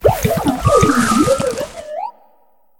Cri d'Arboliva dans Pokémon HOME.